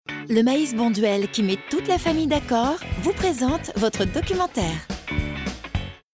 familiale